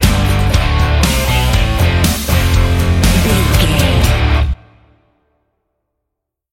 Aeolian/Minor
D
drums
electric guitar
bass guitar
violin
Pop Country
country rock
bluegrass
uplifting
driving
high energy